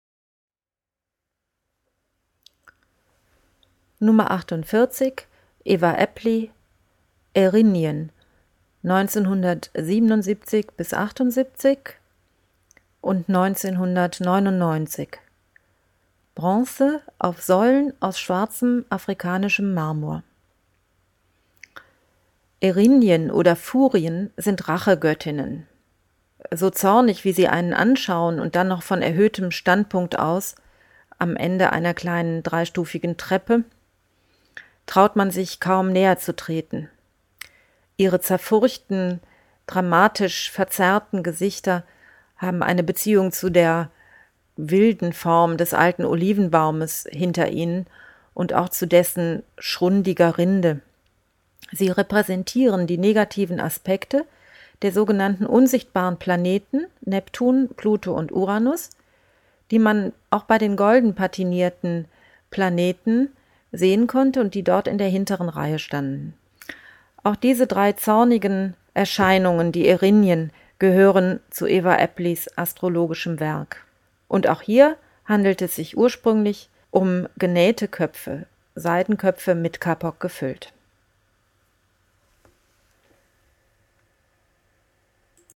audioguide_48_aeppli_furien_giardino-daniel-spoerri.mp3